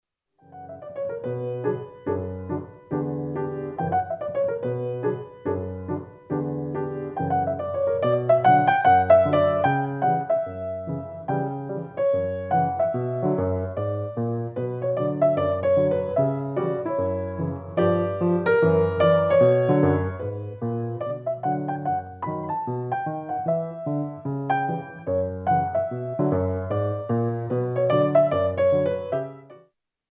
MODERATE